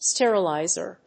発音記号
• / ˈstɛrʌˌlaɪzɝ(米国英語)
• / ˈsterʌˌlaɪzɜ:(英国英語)